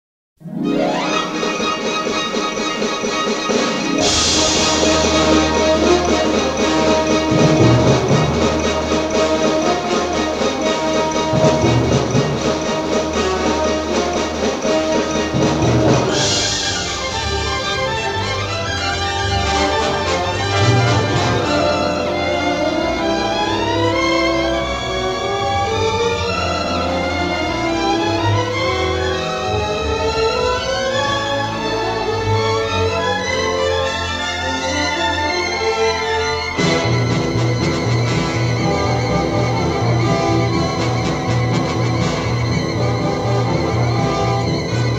a traditional piece of exciting underscore